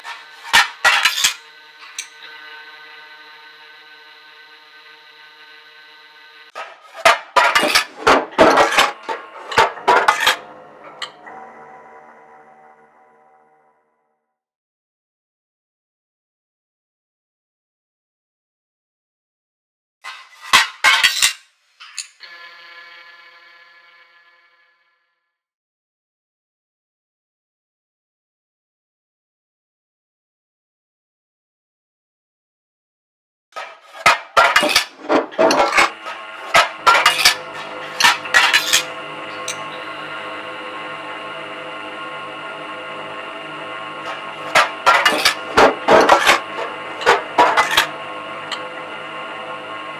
Granulator
I then experiment by playing on a midi and shifting the sample size.
Here are two examples of my original granulator experimentation.
granulator-original.wav